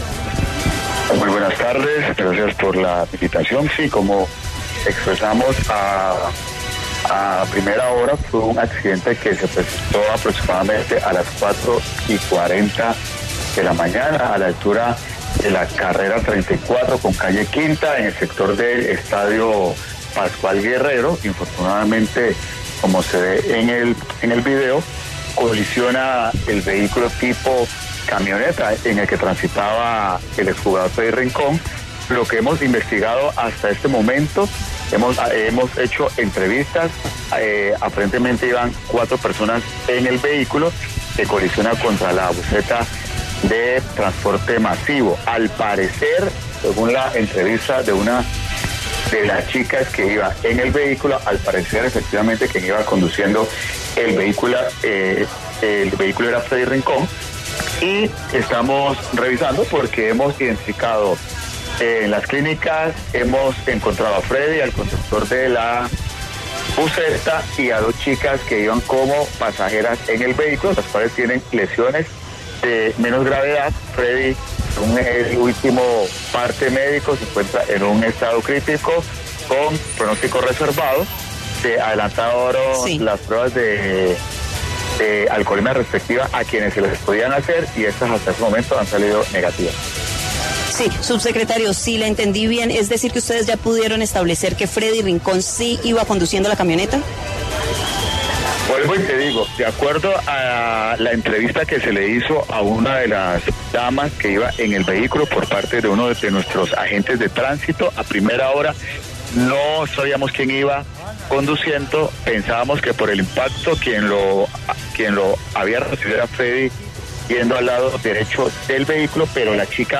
Edwin Candelo, subsecretario de movilidad de Cali habló para Contrarreloj sobre el accidente que sufrió el exfutbolista.